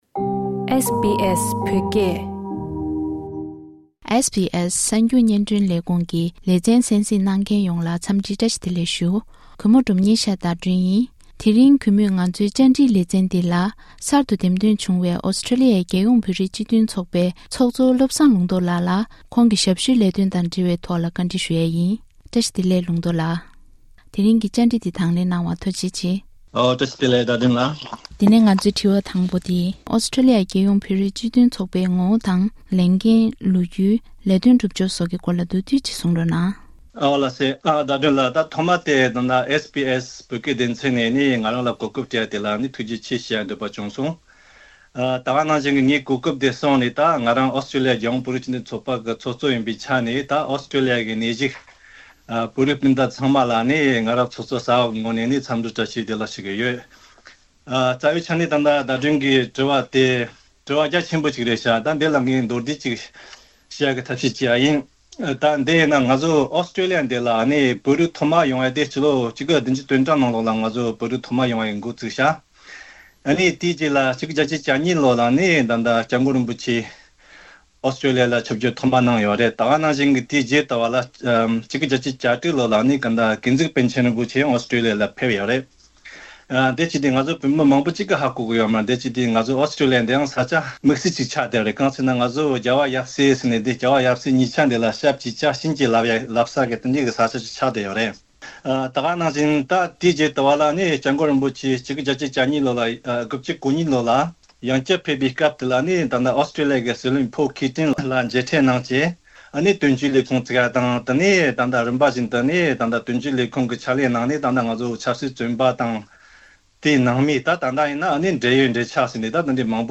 གྲུབ་འབྲས་ཐོན་པར་ཚང་མའི་འབད་བརྩོན་དགོས། Community Interview